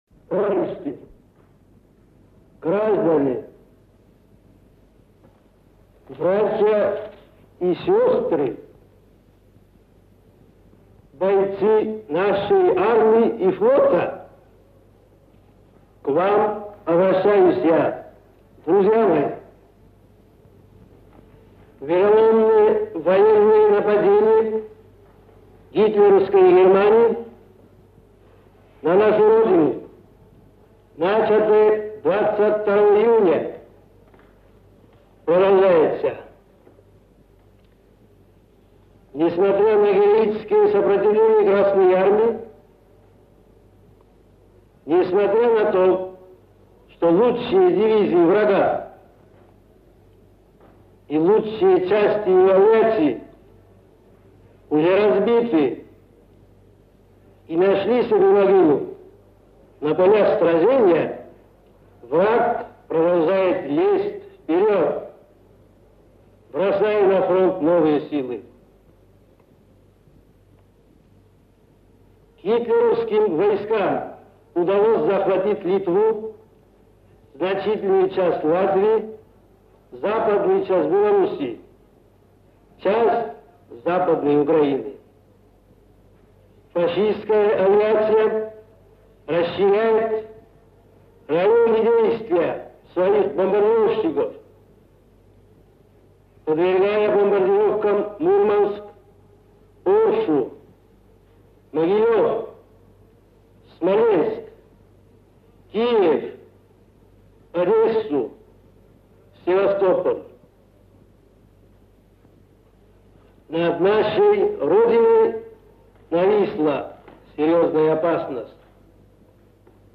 3 июля 1941 года председатель Государственного Комитета Обороны и глава советского правительства Иосиф Сталин впервые с начала войны обратился по радио к советскому народу.